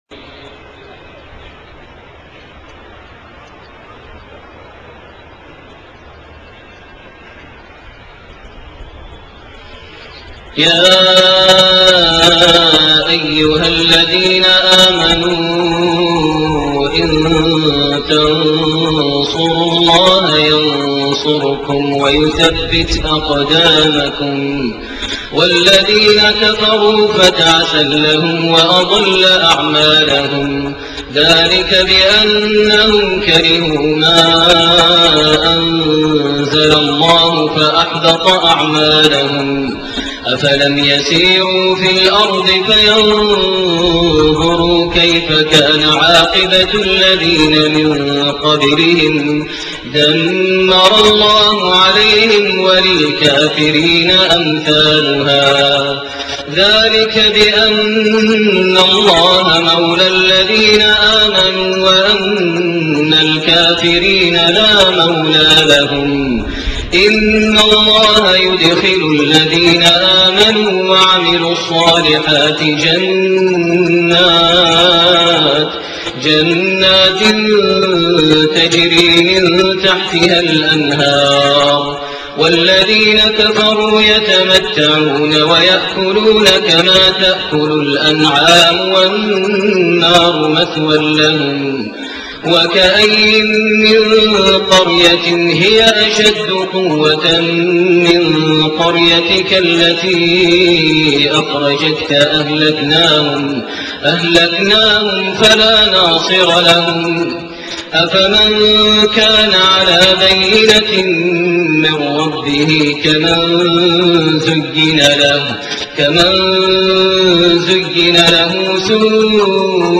صلاة العشاء 3-8-1428 من سورة محمد7-19 > 1428 هـ > الفروض - تلاوات ماهر المعيقلي